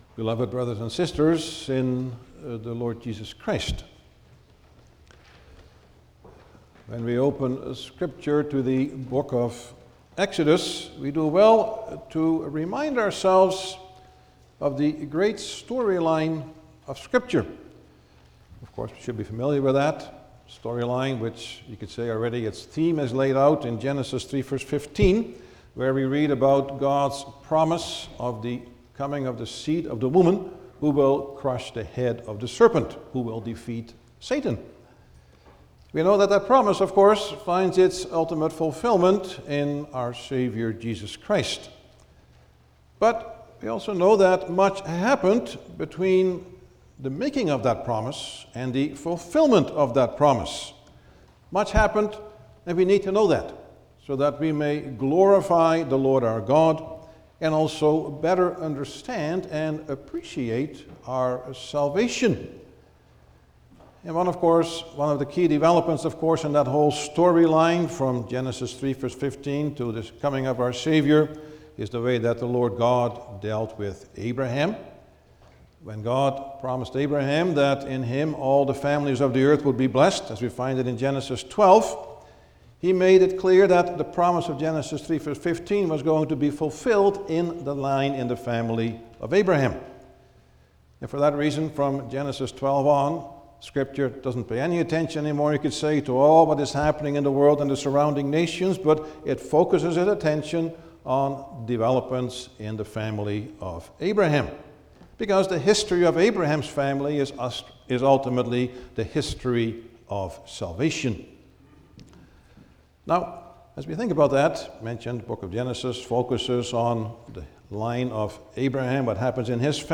Service Type: Sunday morning
10-Sermon.mp3